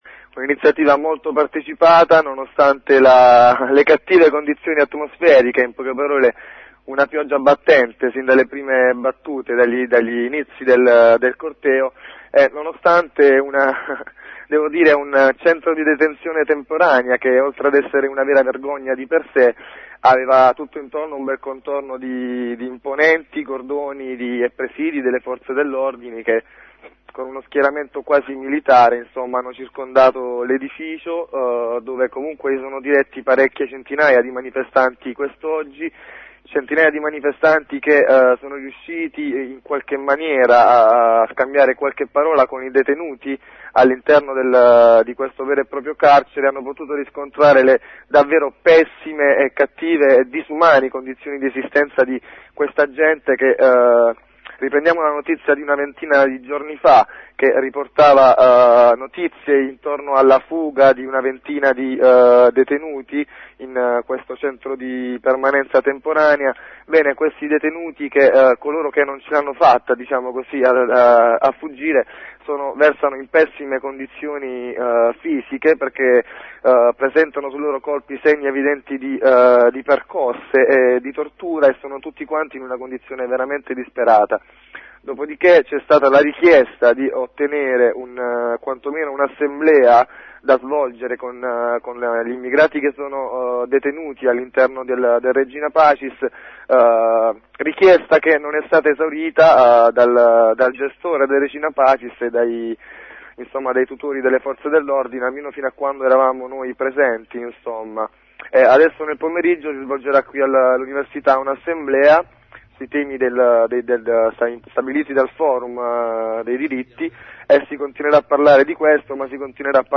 Sotto la pioggia, il corteo svoltosi stamatina a Lecce, contro il centro di permanenza temporanea di S.Foca, il Regina Pacis. Il resoconto della manifestazione.